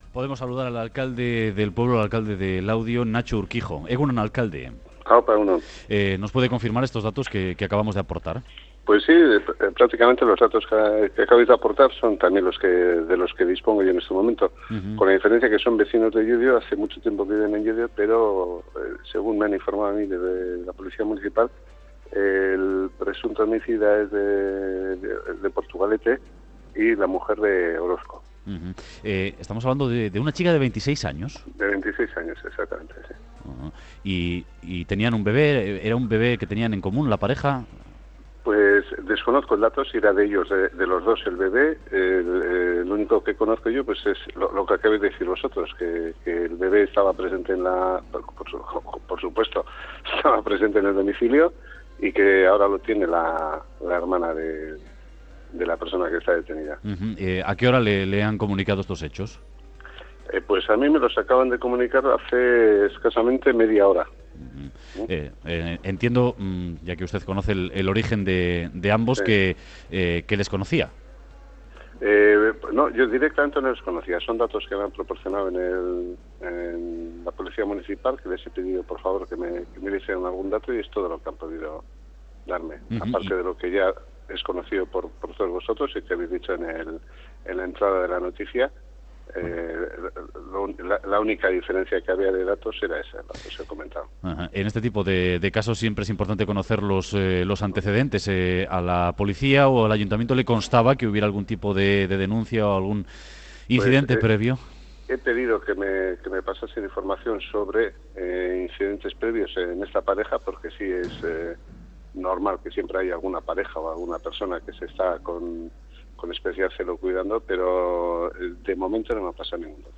Entrevista a Natxo Urkijo, alcalde ce Laudio
Em "Boulevard", de Radio Euskadi, entrevista a Natxo Urkijo, alcalde de Laudio, sobre el asesinato de una mujer cometido esta mañana en esta localidad.